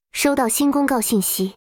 announcement_notification.wav